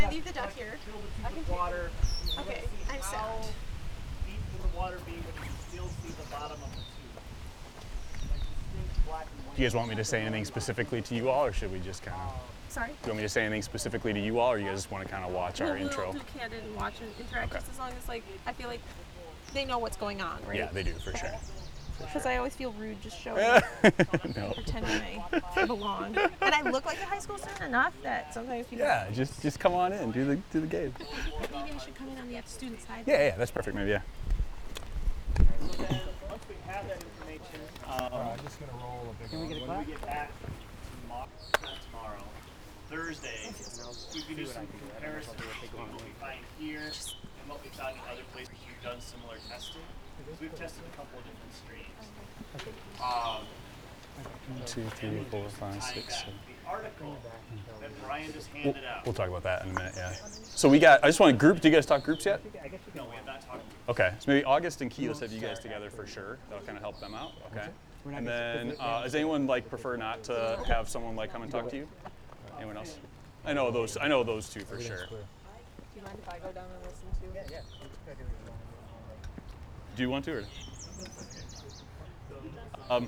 Index of /SkyCloud/Audio_Post_Production/Education/Climate Change/2025-06-03 Creek Rain 2